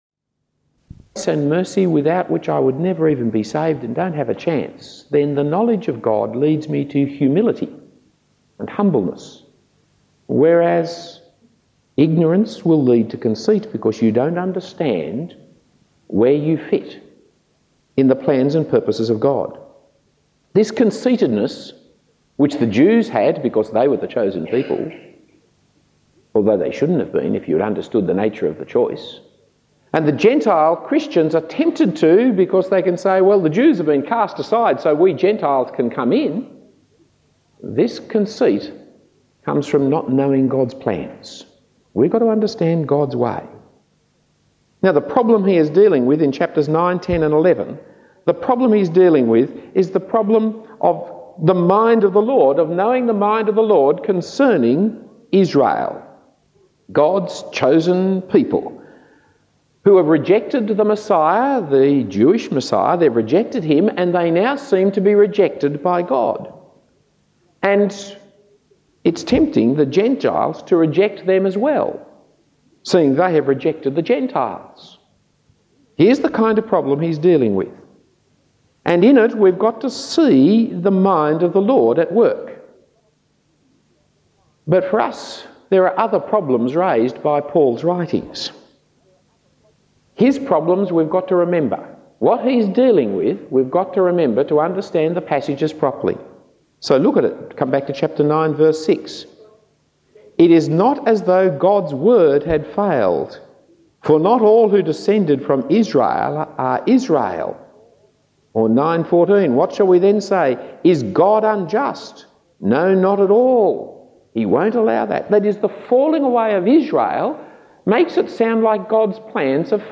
Talk 14 of 20 in the Series Romans 1996/7 given at The Bible Talks congregation.